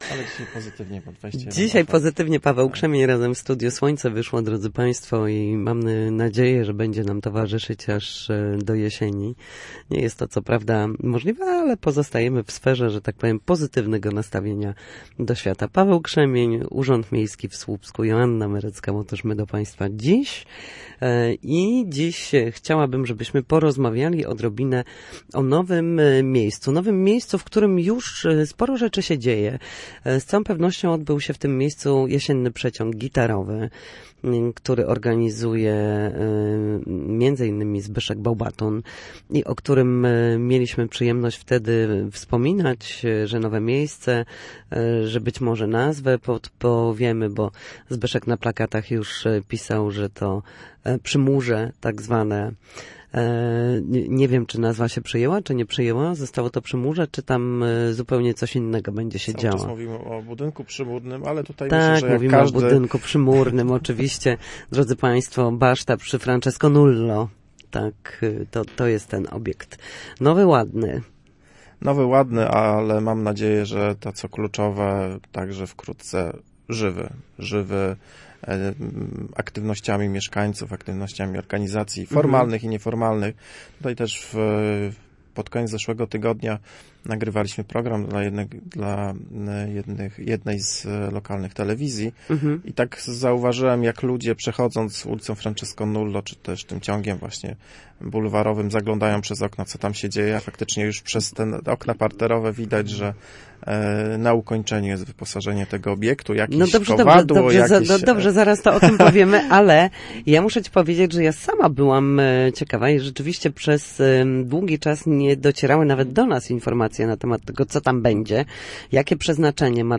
Renowacja murów miejskich i nowy budynek przy bibliotece. Rozmawiamy o ostatnich efektach rewitalizacji